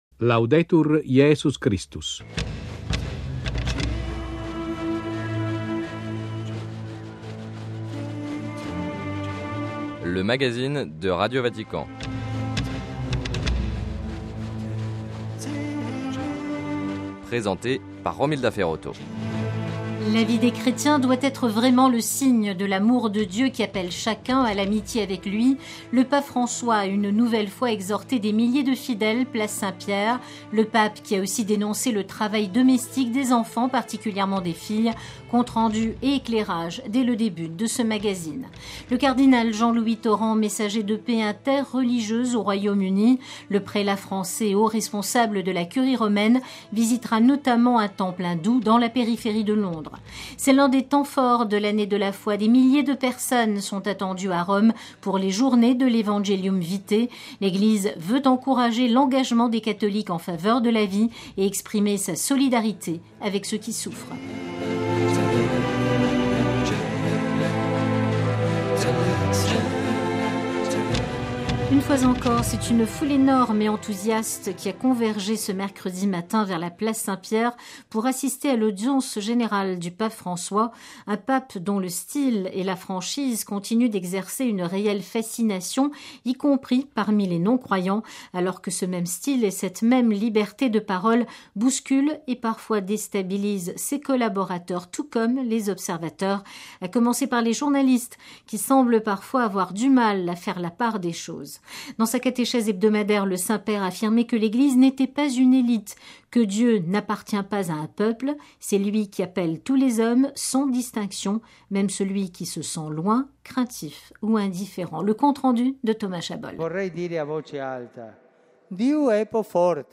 Sommaire : - Compte rendu de l'audience générale du pape François. - Entretien sur le travail des mineurs.